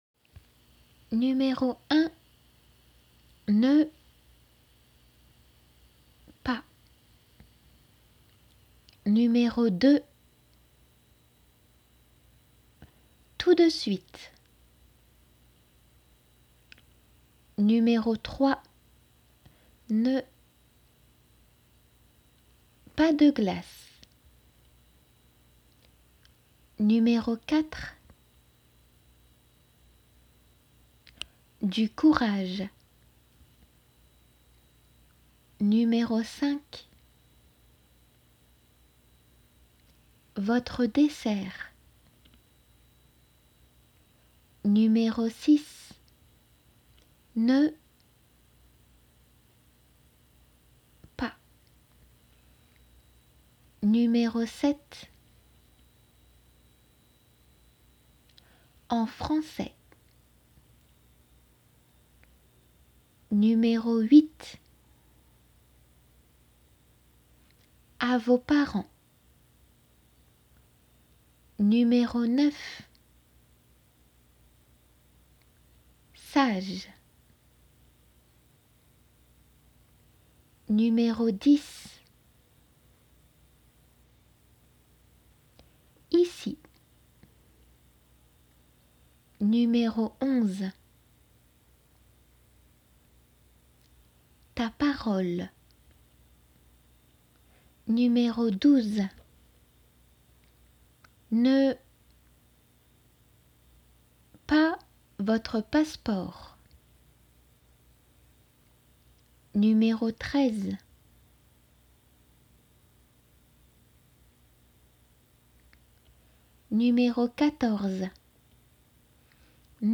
音声　音声fileは解答語句は自分で考えるように読み上げています。練習用です。（実際の仏検では音声は入りません）